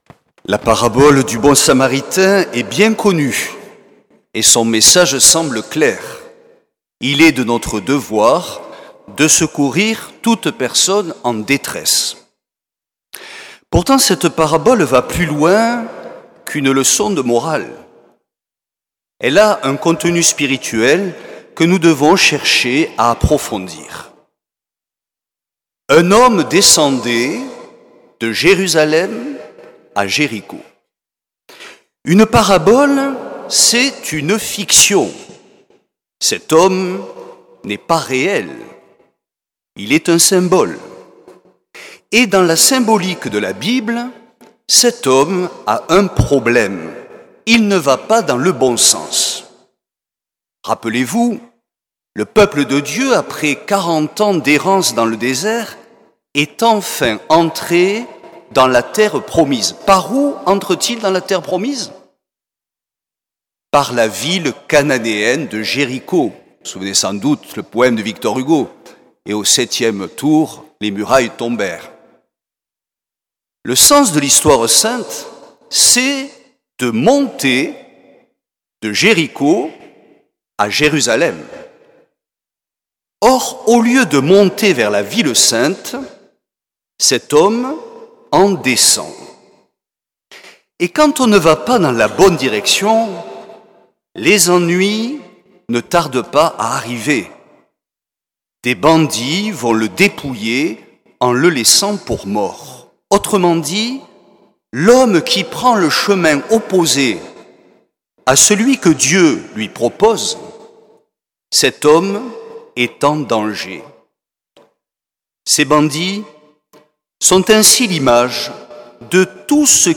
L’enregistrement retransmet l’homélie